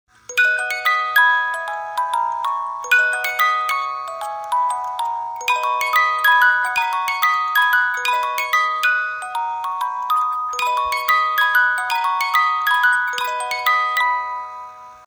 Divertido , Soundtrack